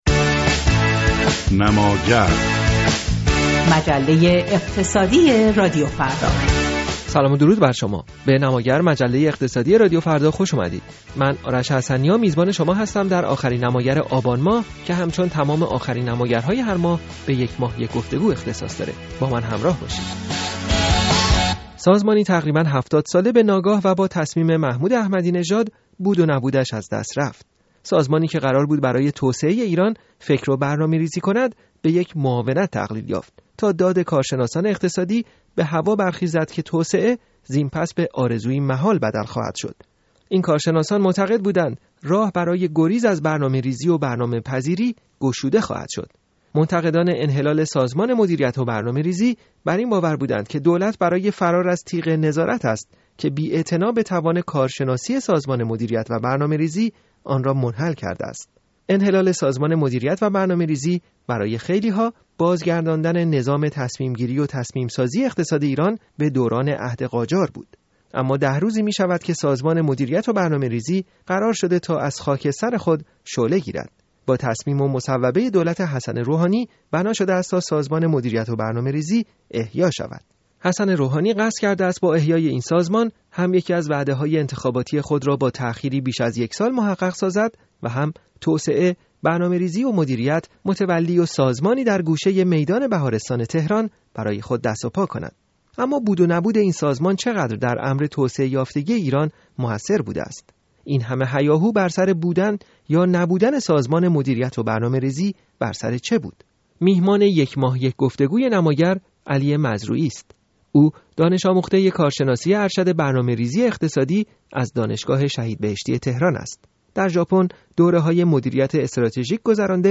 برنامه نماگر؛ گفت‌وگوی